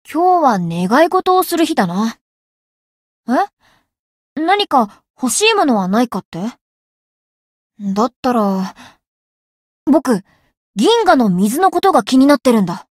灵魂潮汐-莉莉艾洛-七夕（送礼语音）.ogg